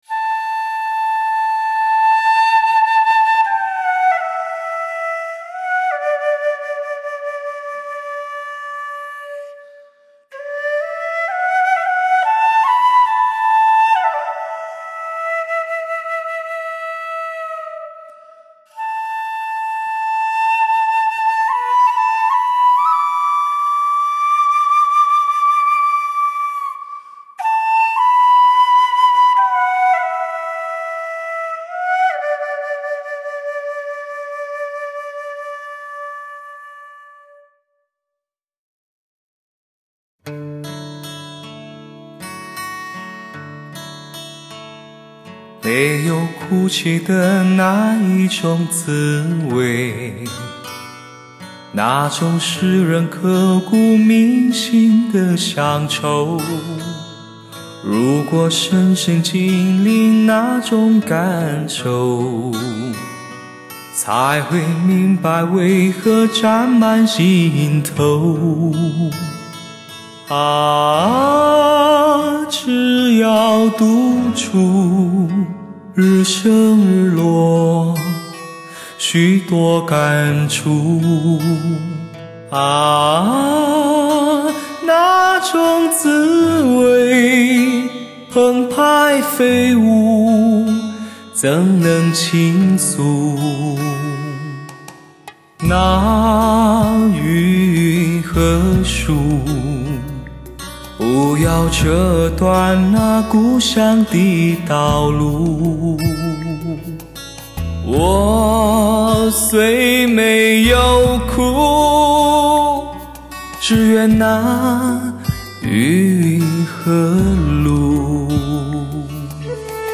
独具音乐多样性的极品汽车CD，蓝光技术的精度修琢，品质更为悦耳的
高临场环绕音效，突破汽车音响狭小空间的限制，让原音清澈通透逼真。
音效真是一级棒